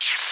13 Harsh Realm Effect 1.wav